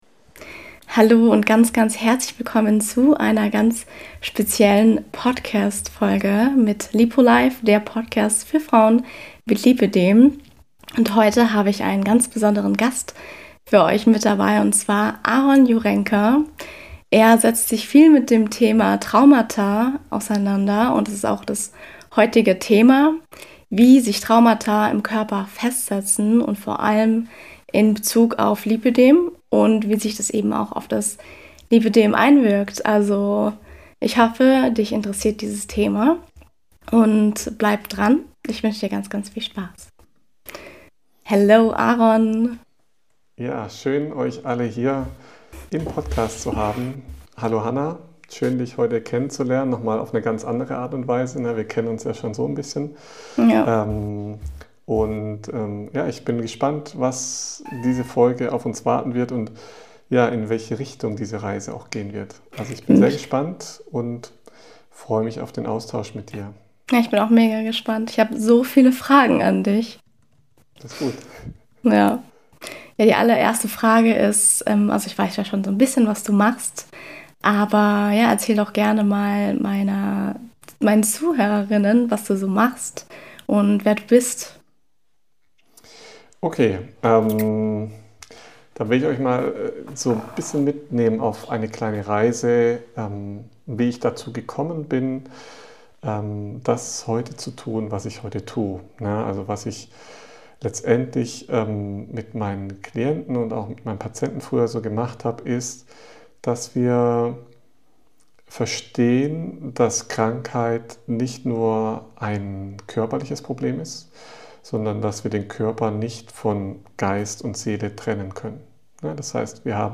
Wie sich Traumata im Körper festsetzen & ihre Auswirkungen auf das Lipödem | Interview